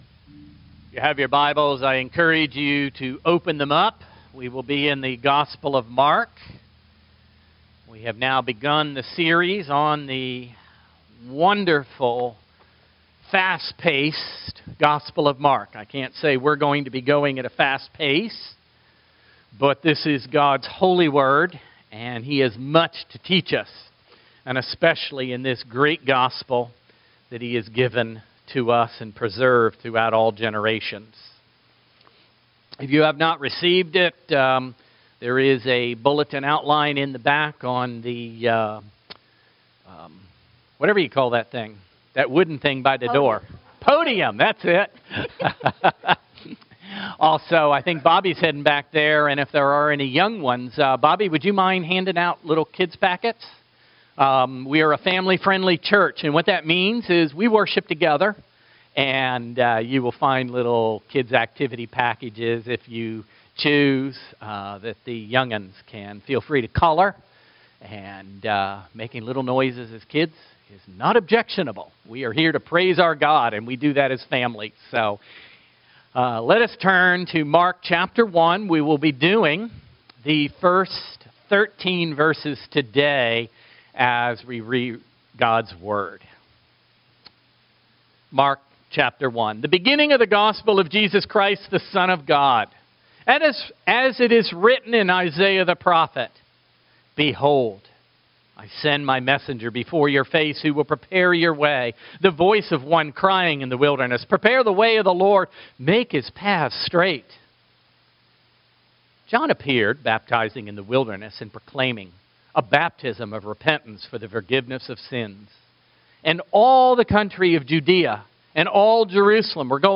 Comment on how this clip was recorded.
Matthew 28:19-20 Service Type: Sunday Morning Worship